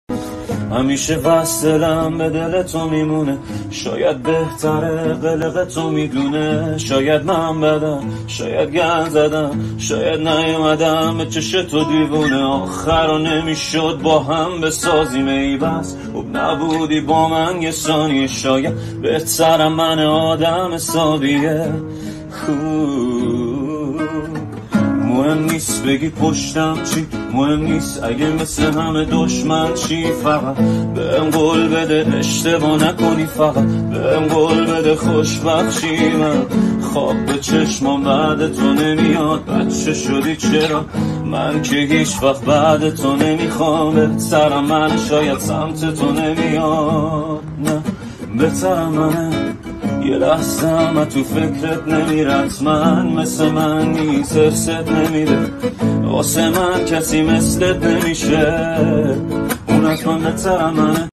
اجرا با گیتار